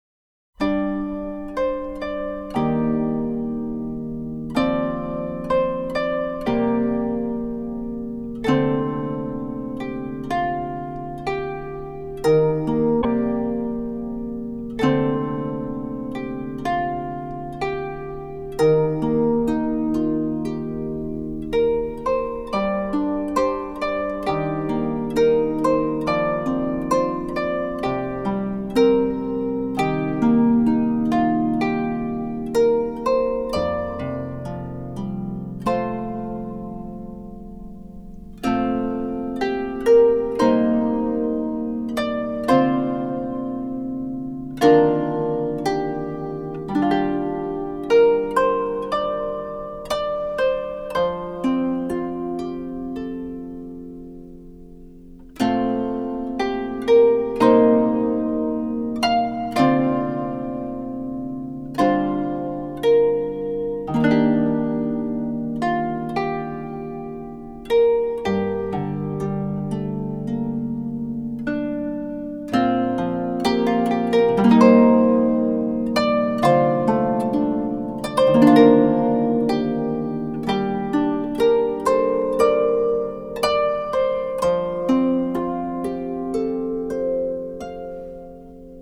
-   世界音樂 (159)
★ 創新技巧與手法表現出傳統鋼弦音色更具詩意的一面，帶您一窺日本箏的浪漫與瀟灑！
那安逸和諧的韻律，平滑豐盈的感覺，在乾淨清澈中體現出沁人心脾的美感。